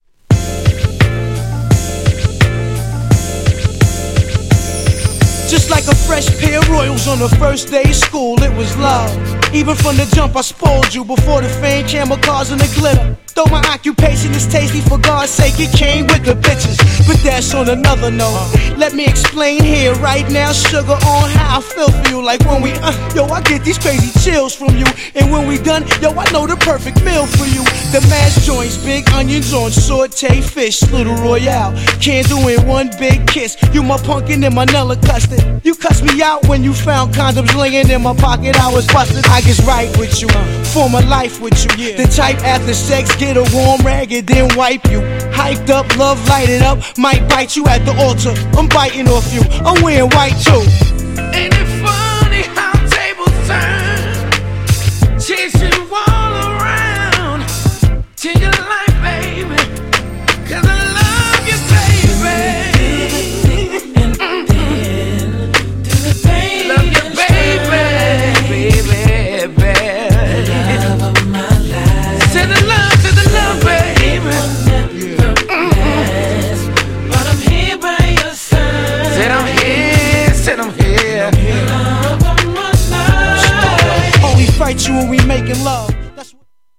アコースティックな音色に抜群の歌唱力で全編心地よく聞かせてくれる極上R&B!!
GENRE R&B
BPM 71〜75BPM